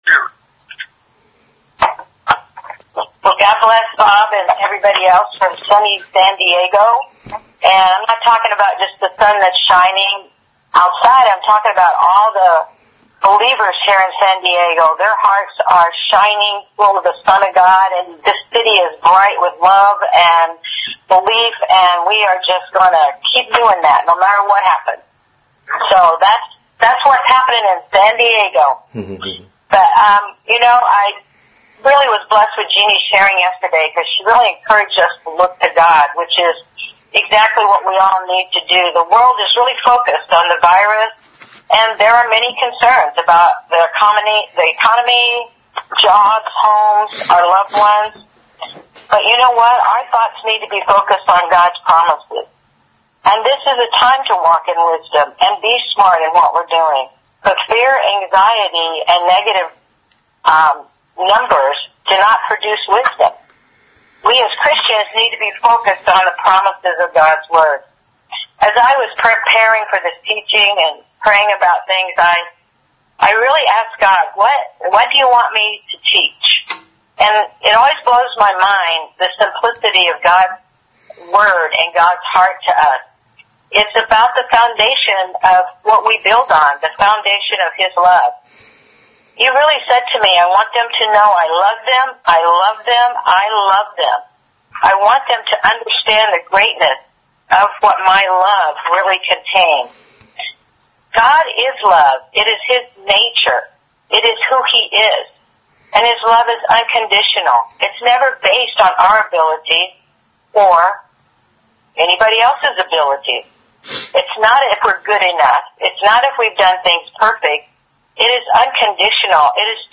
Conference Call Fellowship